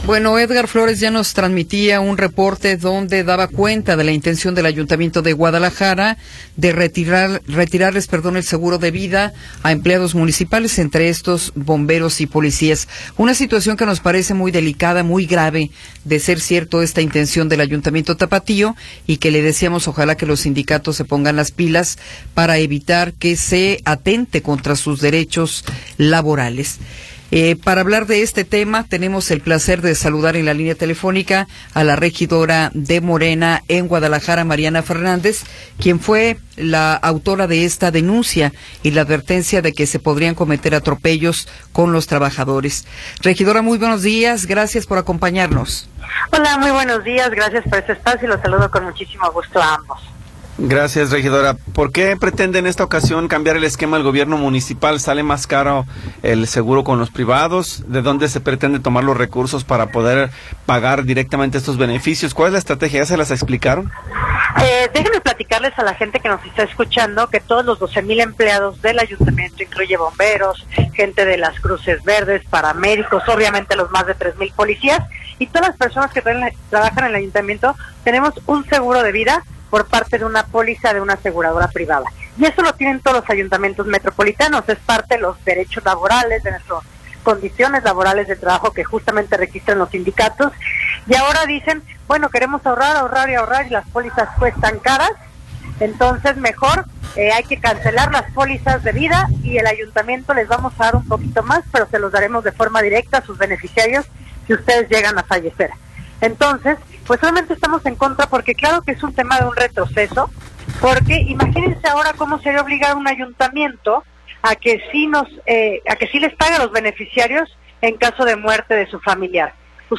Entrevista con Mariana Fernández
Mariana Fernández, regidora de Guadalajara, nos habla sobre la intención del ayuntamiento de Guadalajara de eliminar el seguro de vida a 12 mil empleados incluyendo policías y bomberos.